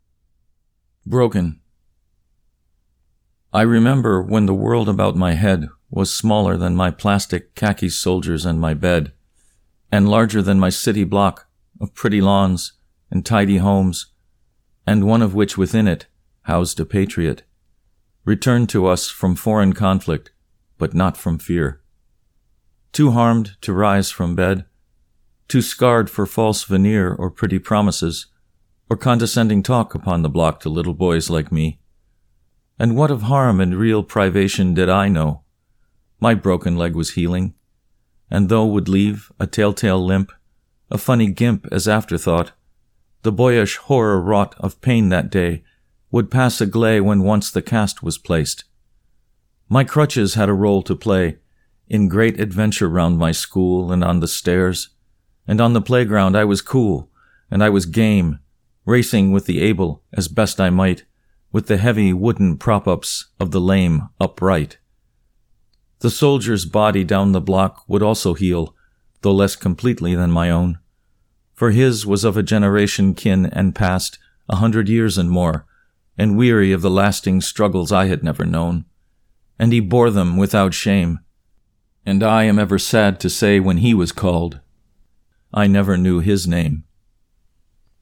Broken (Recitation)